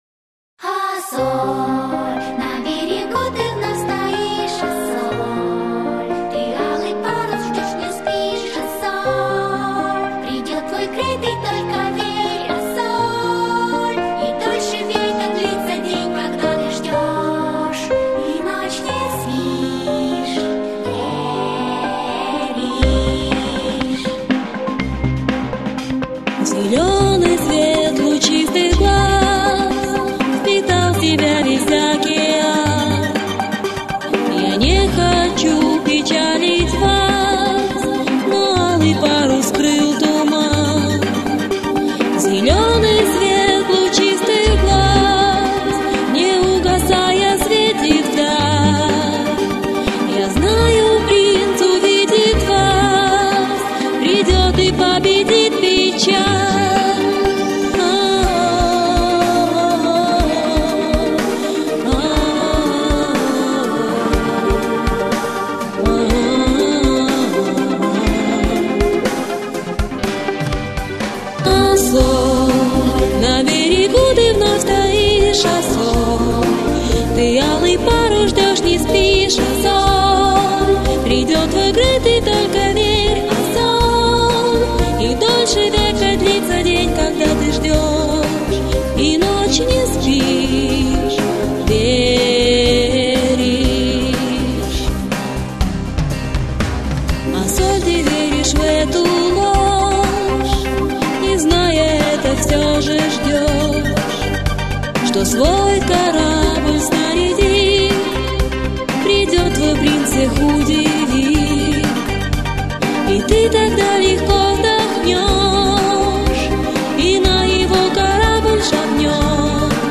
Ознакомительная версия диска
включающий в себя записи из ее семейного архива.